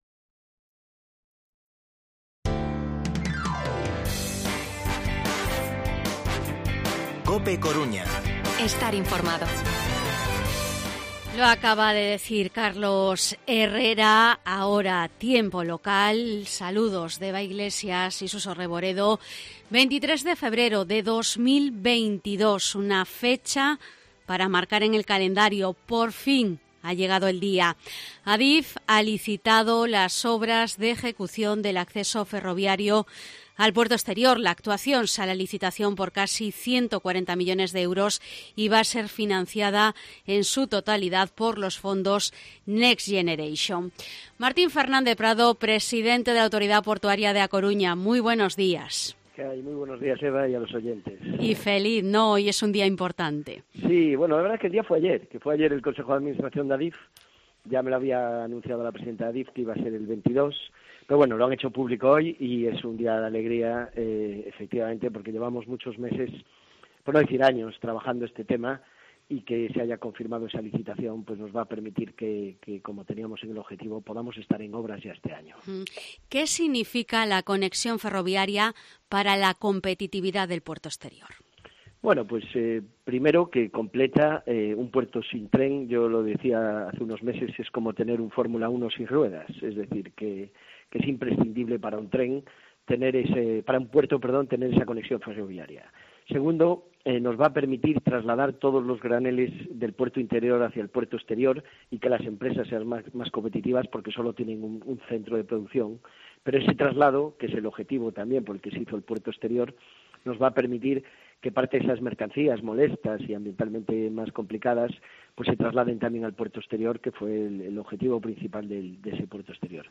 Entrevista Martín Fernández Prado, presidente Autoridad Portuaria de A Coruña (licitación conexión ferroviaria). Sabotaje camiones basura.